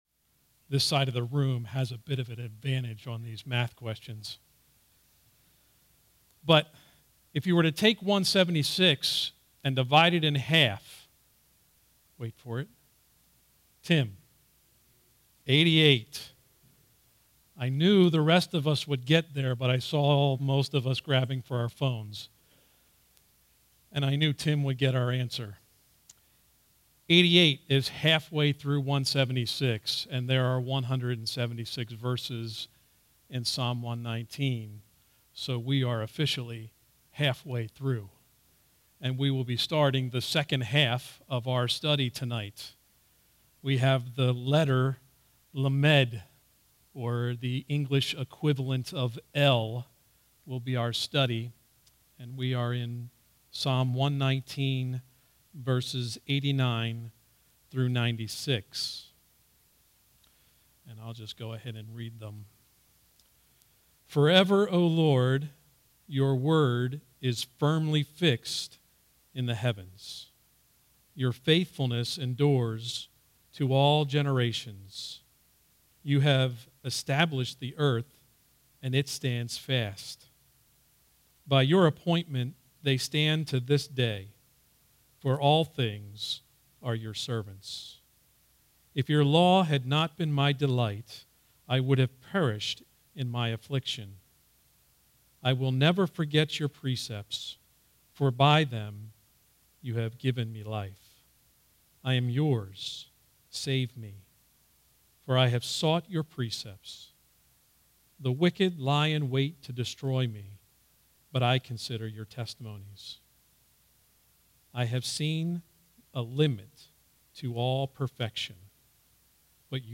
All Sermons Psalm 119:89-96